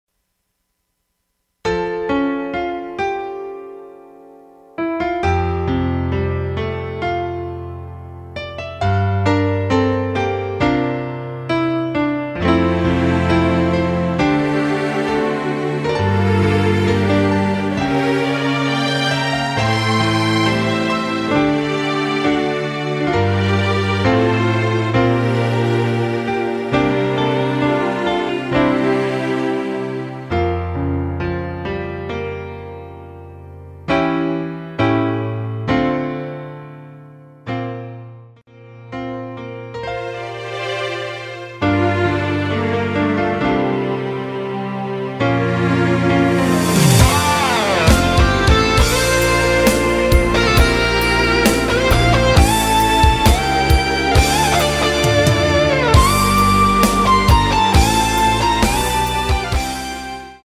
MR입니다.
앞부분30초, 뒷부분30초씩 편집해서 올려 드리고 있습니다.
중간에 음이 끈어지고 다시 나오는 이유는